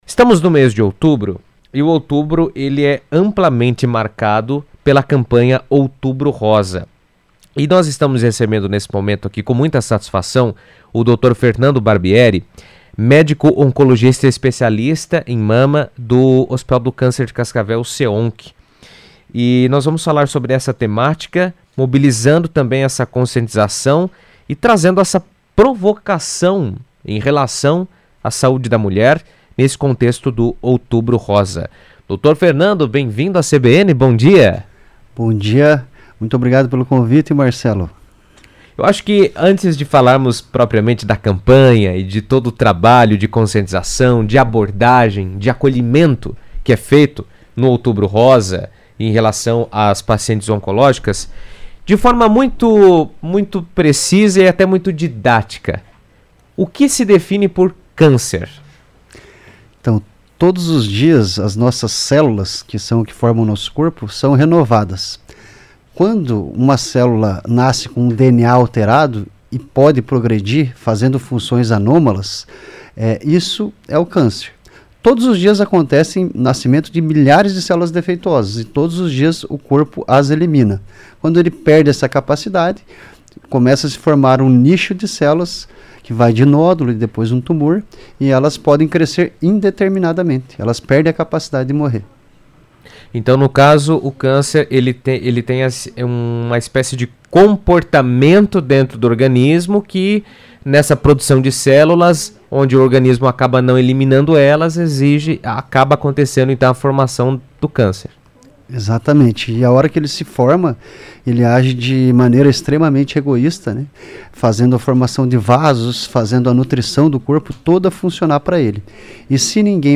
No Outubro Rosa, o CEONC – Hospital do Câncer de Cascavel realiza a campanha “Seu Corpo é Sua Morada”, que incentiva o autocuidado feminino como forma de prevenção ao câncer de mama. Em entrevista à CBN